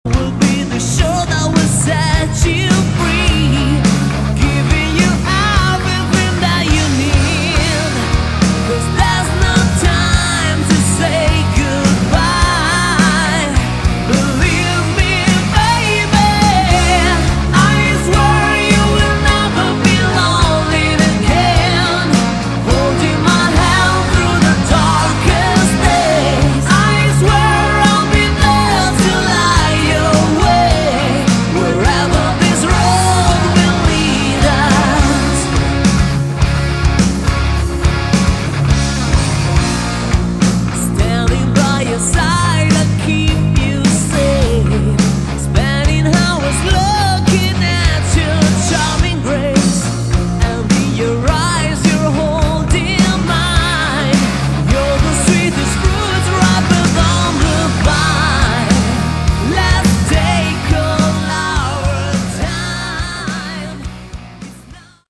Category: Melodic Rock
vocals
guitars
keyboards
bass
drums
percussion
backing vocals